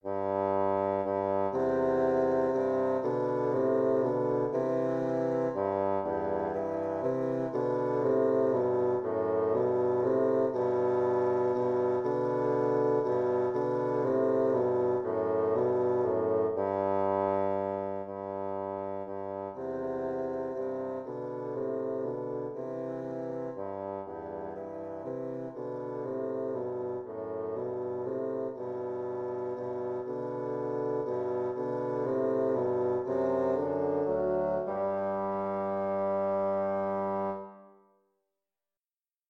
bassoon music